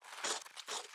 ttr_s_ara_cig_skateRun.ogg